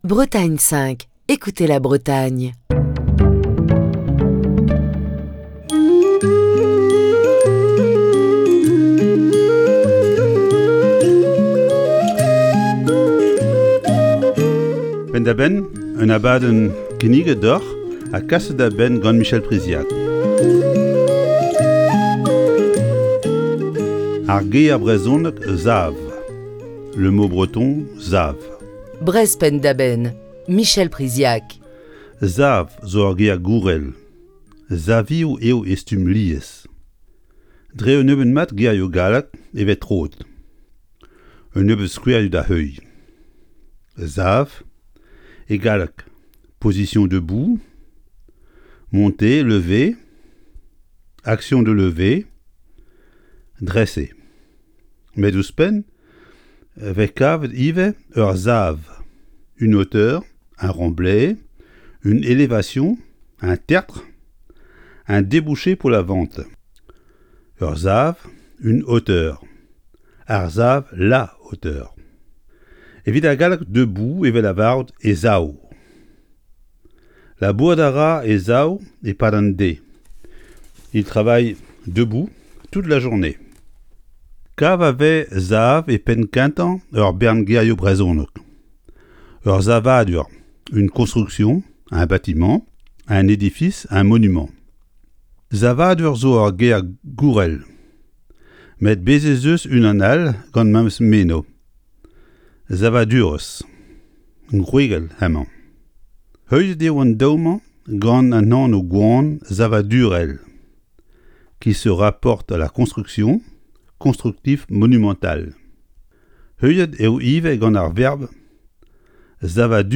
C'est jeudi, et c'est cours de breton dans Breizh Penn da Benn.